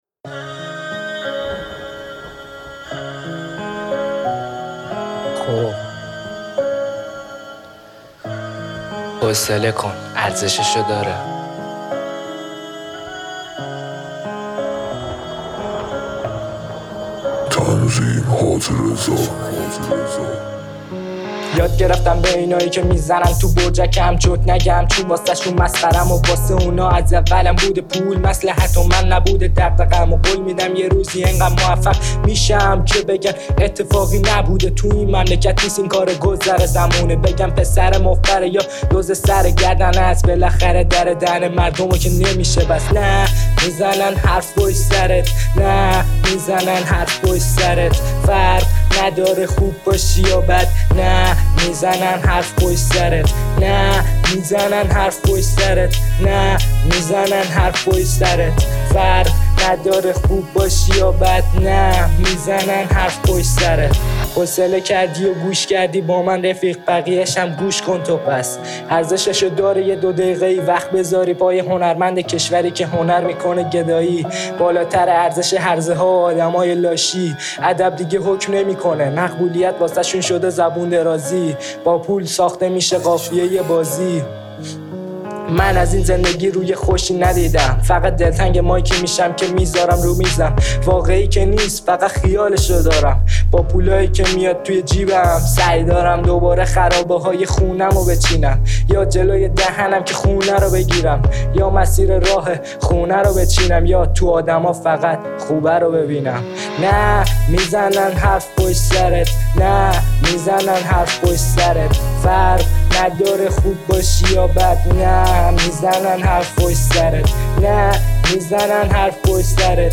تهیه شده در(استدیو رکورد)